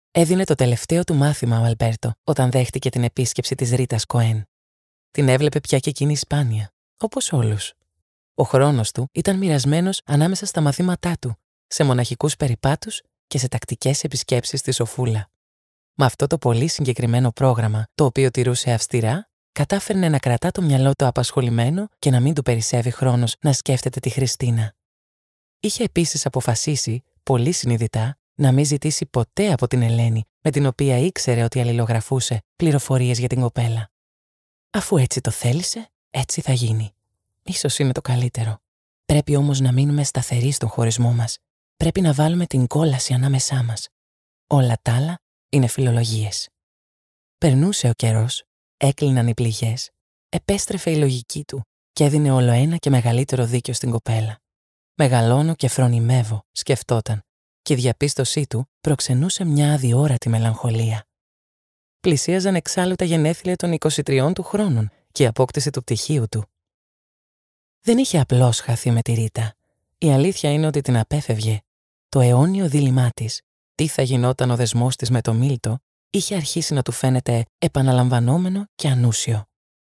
Female
Approachable, Assured, Authoritative, Character, Children, Confident, Conversational, Corporate, Deep, Energetic, Engaging, Friendly, Natural, Reassuring, Smooth, Versatile, Warm
Microphone: MKH 416 Sennheiser Shotgun
Audio equipment: Professionally sound-proofed home studio room, RME Fireface UCX II, Audio-Technica ATH M50x Headphones, Kali Audio studio monitors